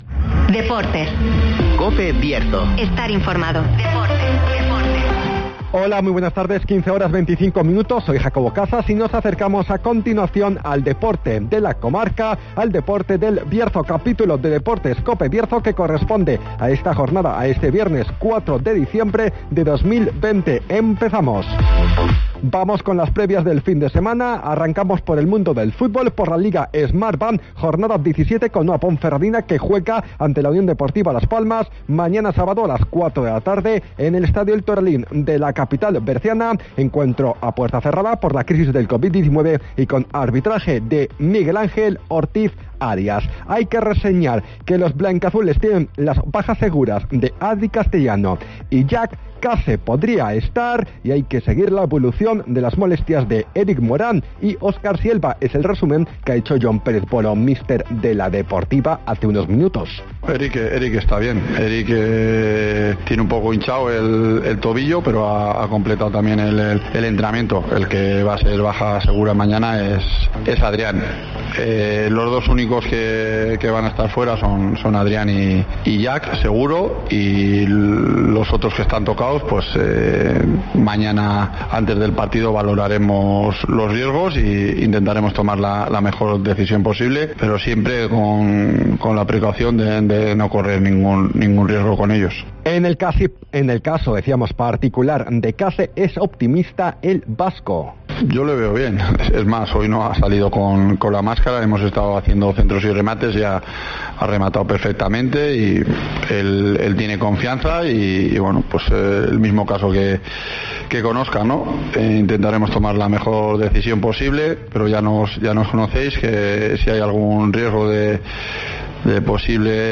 AUDIO: Previa del Ponferradina- Las Palmas con declaraciones de Jon Pérez Bolo. Además, otros apuntes del fin de semana deportivo